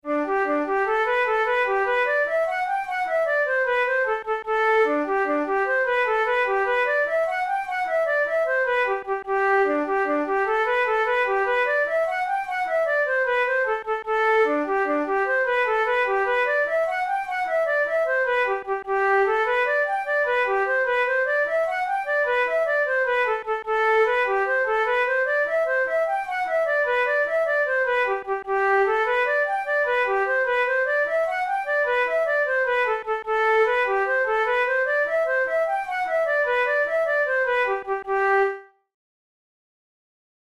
Jigs, Traditional/Folk
Traditional Irish jig